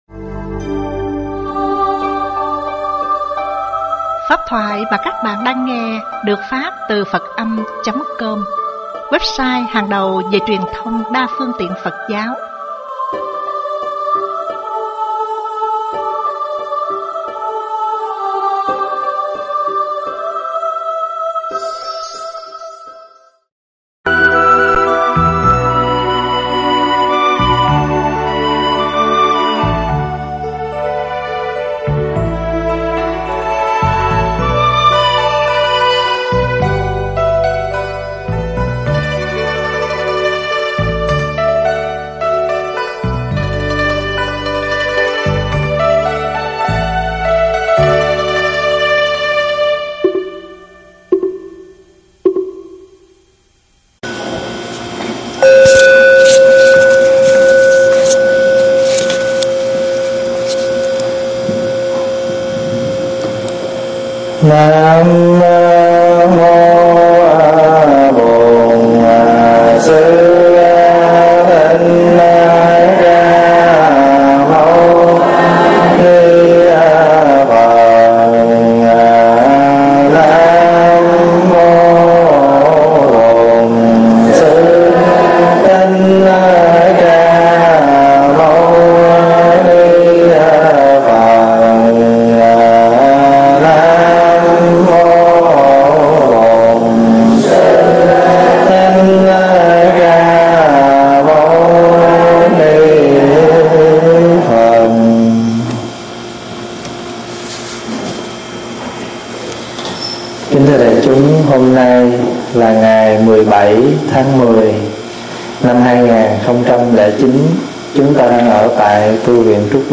Nghe Mp3 thuyết pháp Kinh Địa Tạng giảng giải 7b
giảng tại tu viện Trúc Lâm